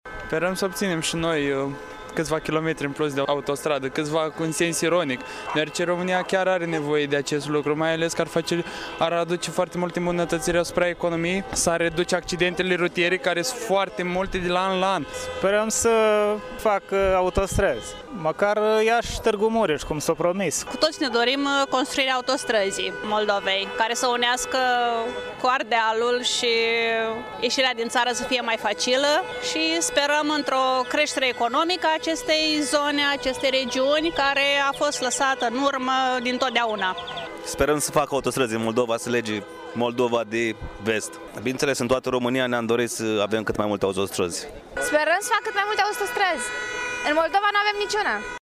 La protestul de la Palatul Culturii au participat aproximativ o mie de persoane:
15-martie-rdj-17-vox-Iasi.mp3